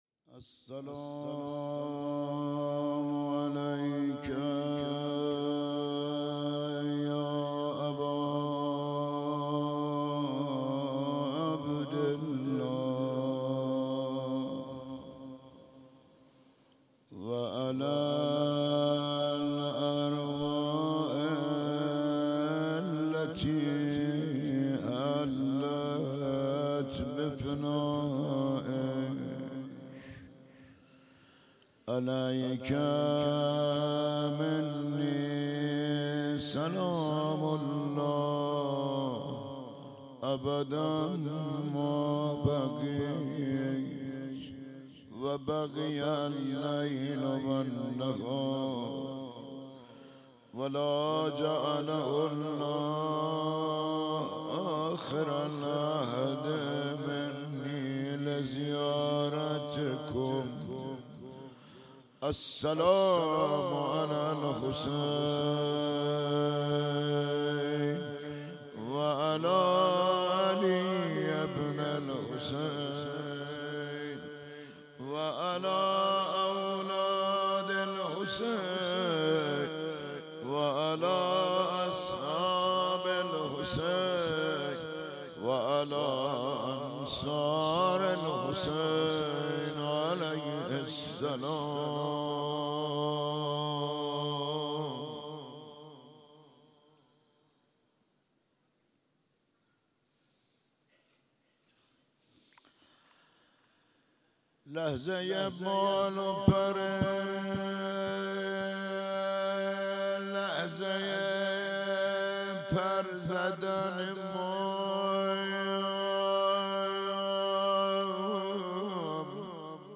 شب پنجم محرم 96 - روضه